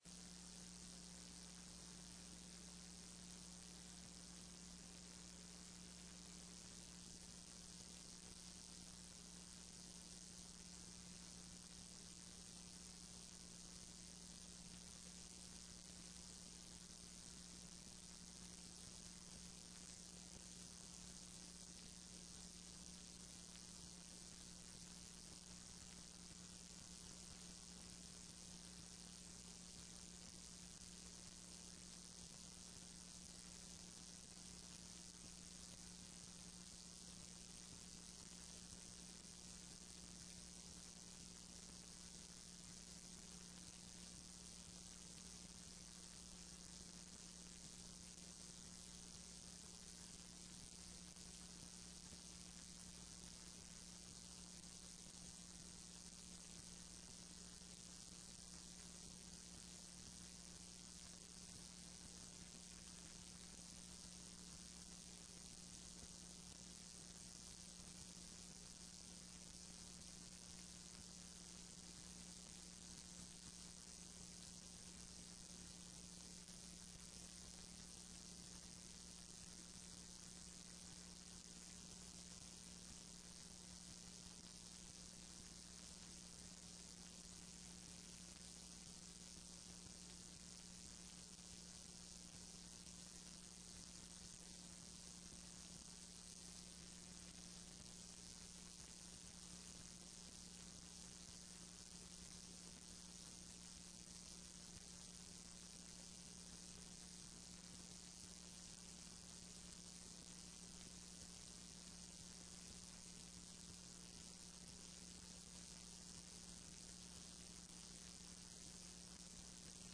Sessão de julgamento do TRE-ES